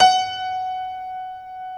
Index of /90_sSampleCDs/InVision Interactive - Lightware VOL-1 - Instruments & Percussions/GRAND PIANO1